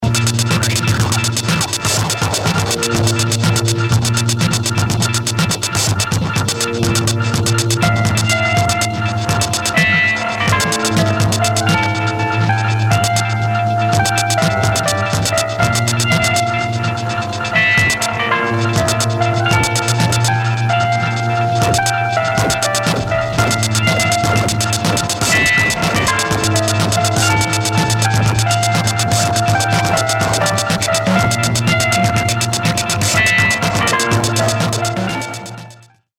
Медитативная музыка